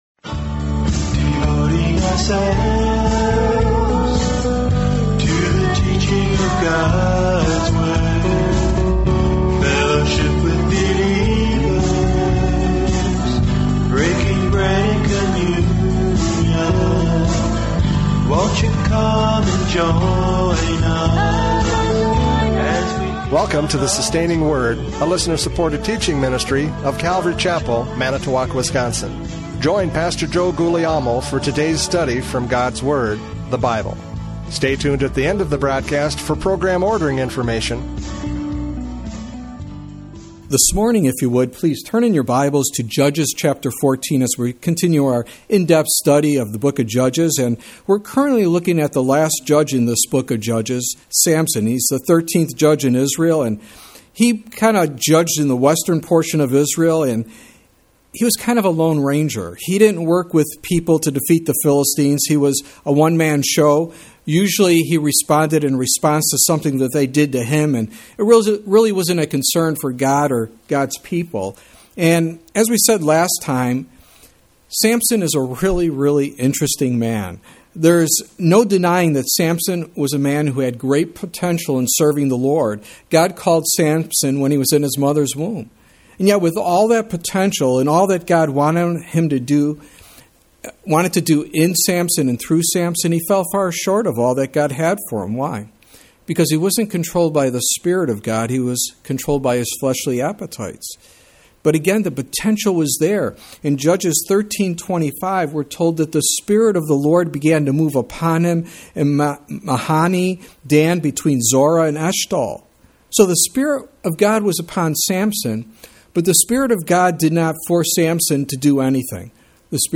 Judges 14:1-7 Service Type: Radio Programs « Judges 14:1-4 No Respect!